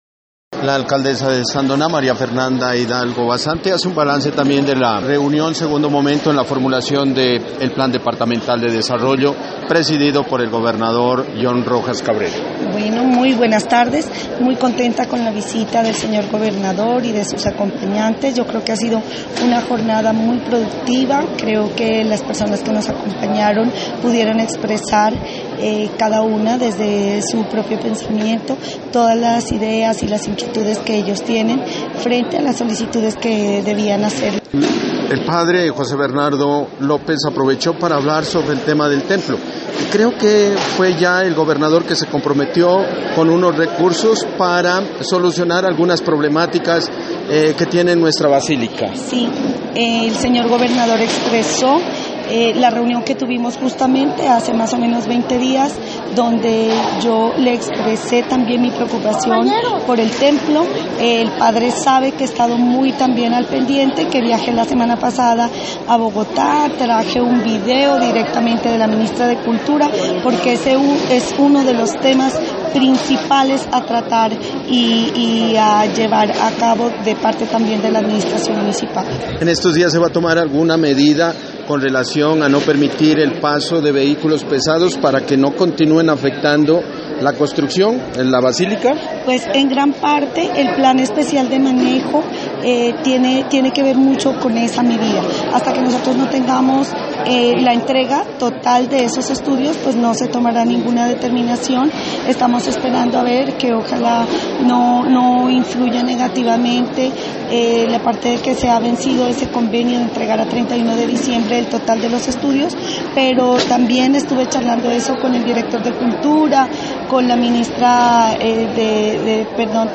Entrevista con la alcaldesa de Sandoná María Fernanda Hidalgo Basante: